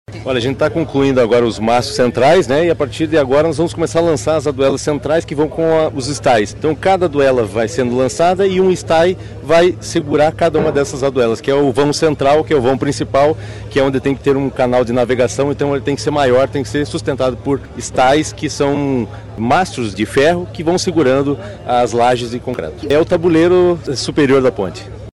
Fernando Furiatti, diretor-presidente do Departamento de Estradas de Rodagem do Paraná (DER/PR), falou sobre essa complexa parte da obra.